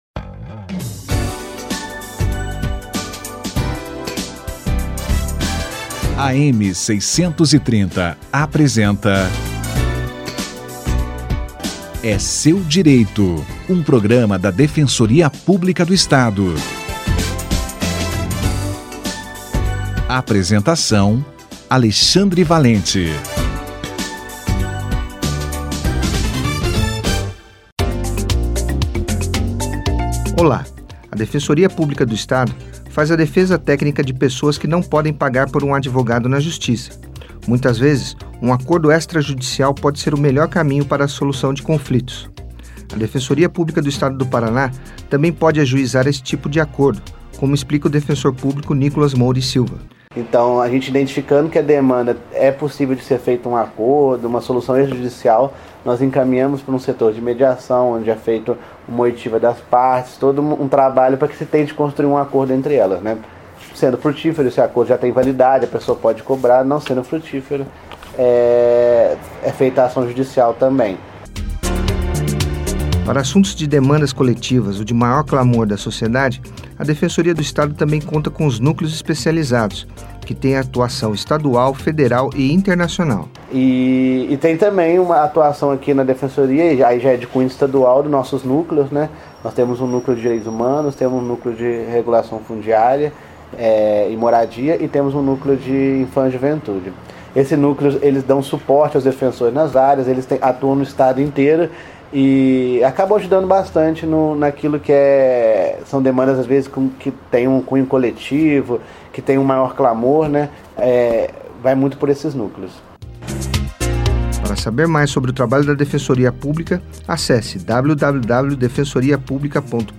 Acordos extrajudiciais - Entrevista